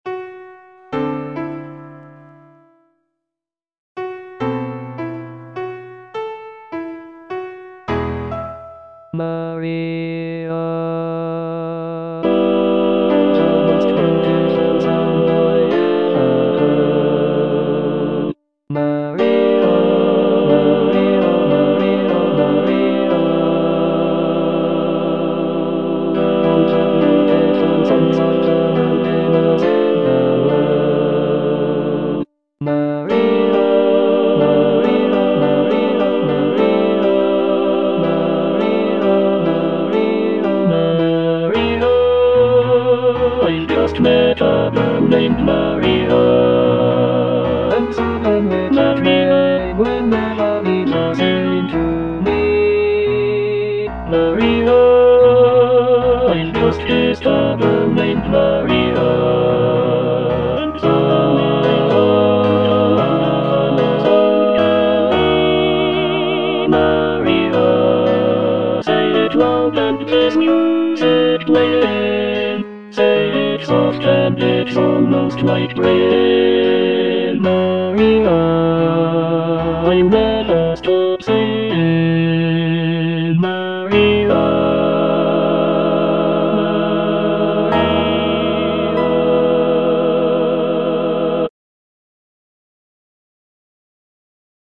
All voices
choral arrangement